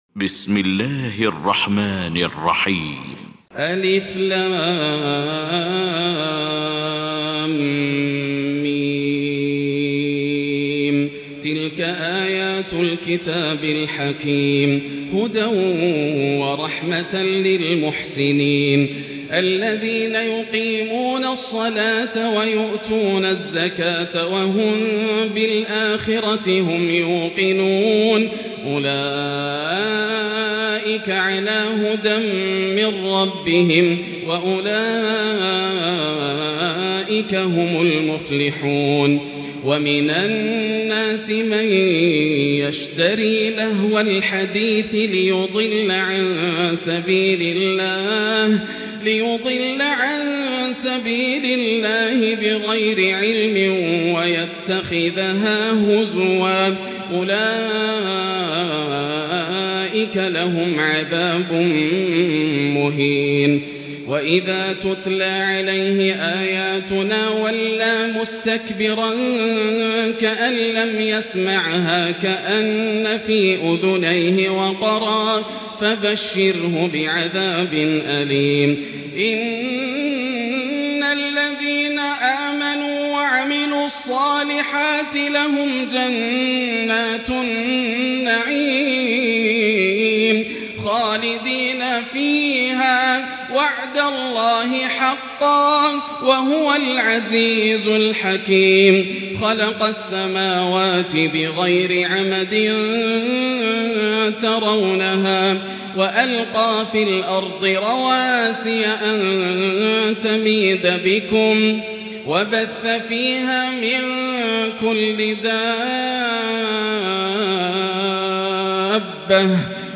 المكان: المسجد الحرام الشيخ: فضيلة الشيخ ياسر الدوسري فضيلة الشيخ ياسر الدوسري لقمان